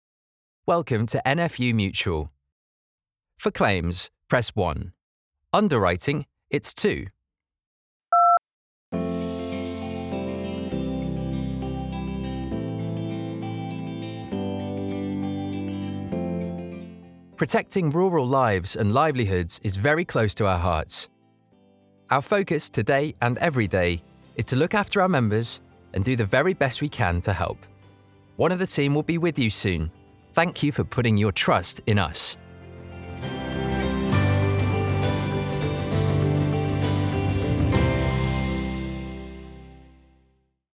Engels (Brits)
Commercieel, Natuurlijk, Stoer, Toegankelijk, Vriendelijk
Telefonie